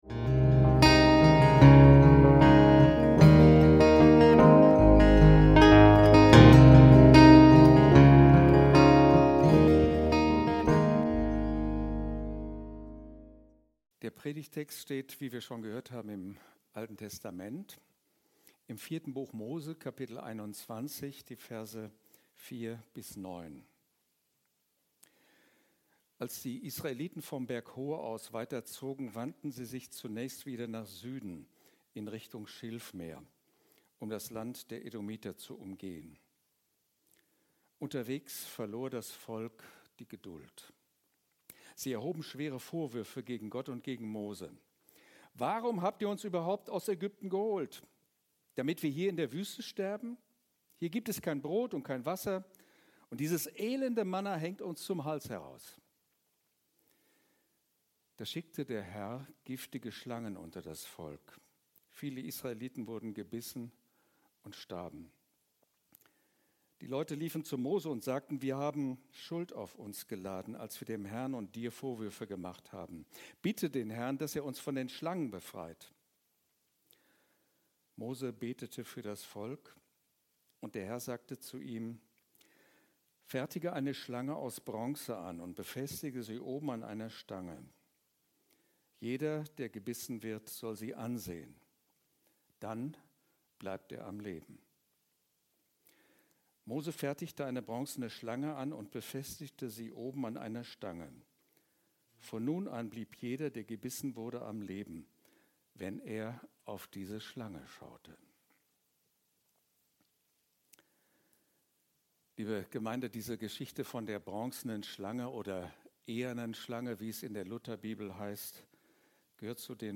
Der Blick auf den Gekreuzigten - Predigt vom 19.10.2025